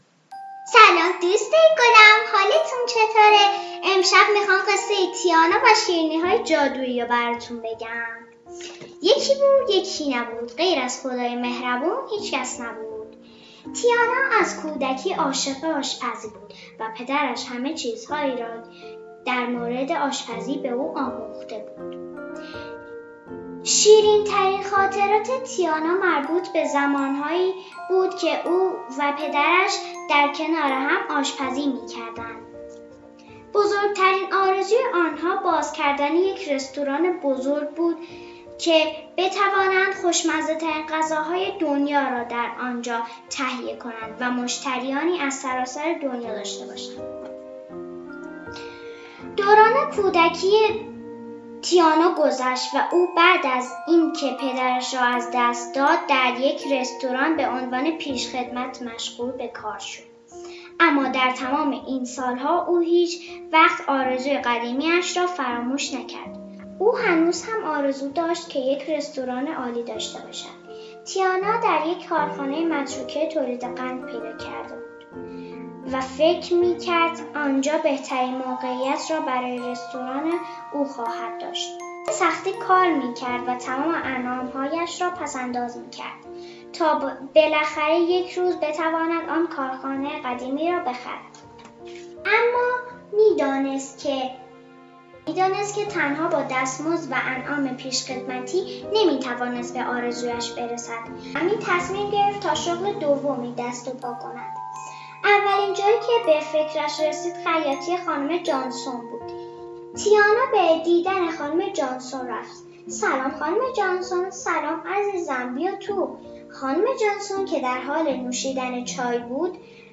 قصه کودکان